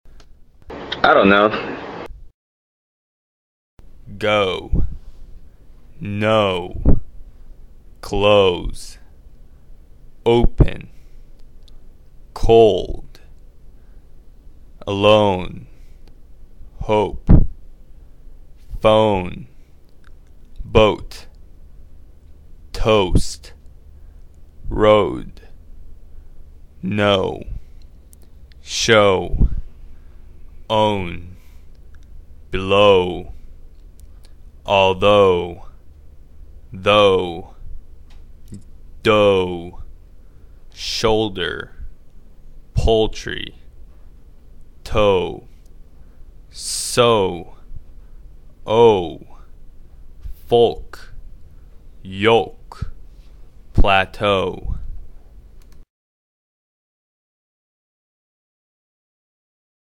21 /ɔʊ/ OK 37 /p/ /b/